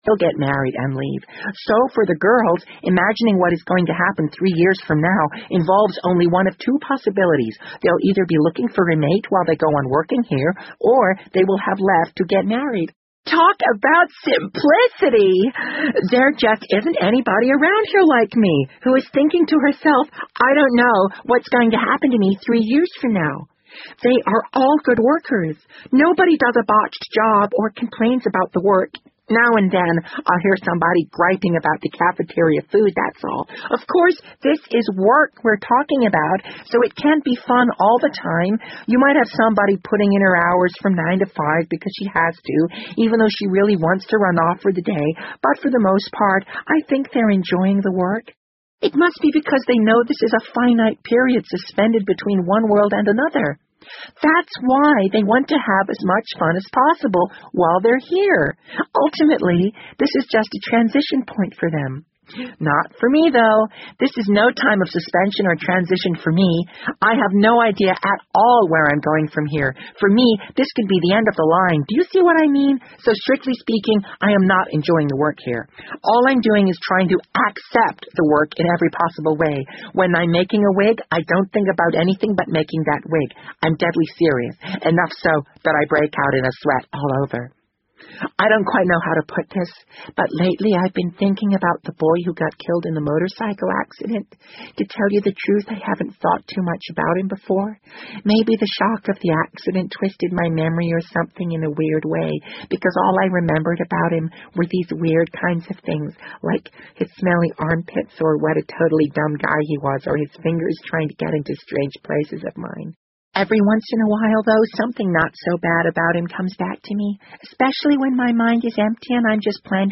BBC英文广播剧在线听 The Wind Up Bird 011 - 17 听力文件下载—在线英语听力室